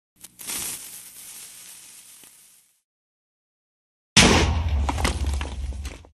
tnt-boom.mp3